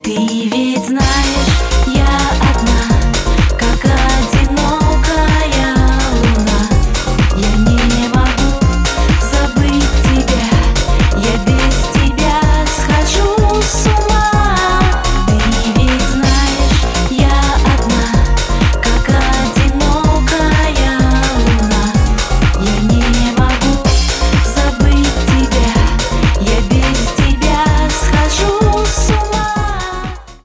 • Качество: 128, Stereo
электроника